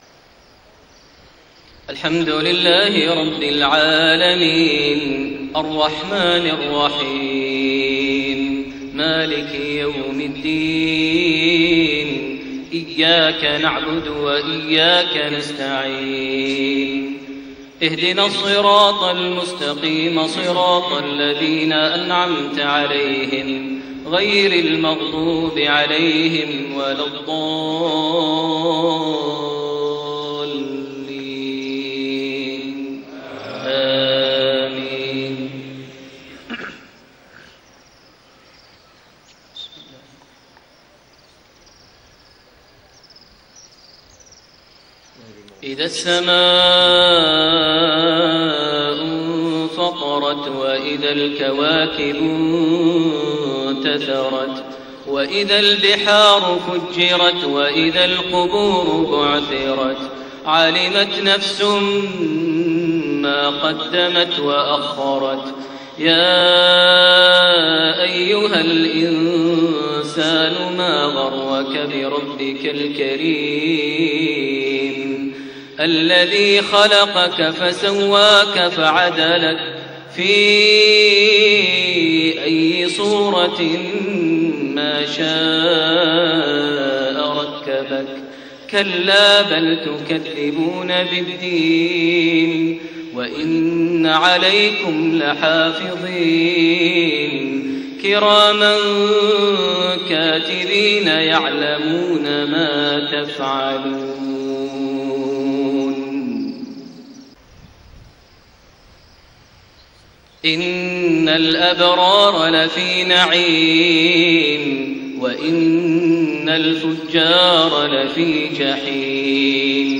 صلاة المغرب5-4-1432 سورة الإنفطار > 1432 هـ > الفروض - تلاوات ماهر المعيقلي